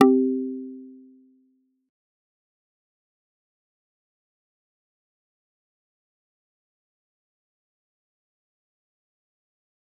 G_Kalimba-C4-mf.wav